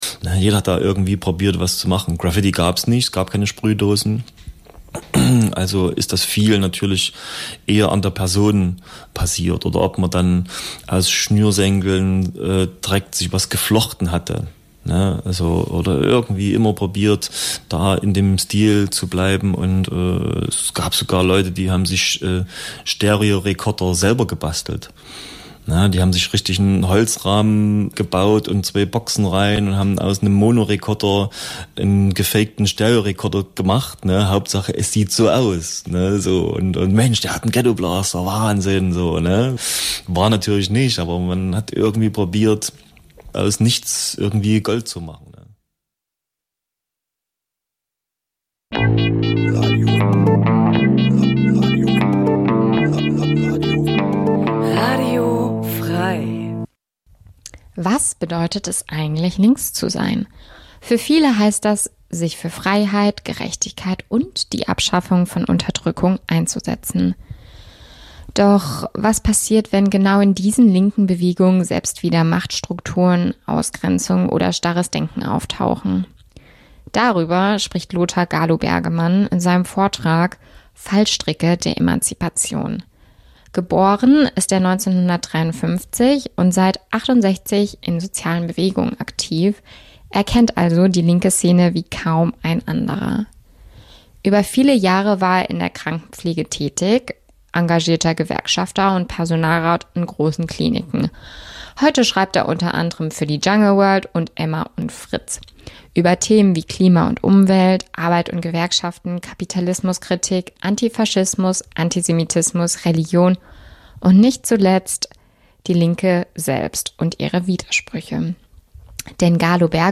Vortrag und Diskussion
Aufzeichnung vom 14. Mai 2025, 18.00 Uhr, UNI Jena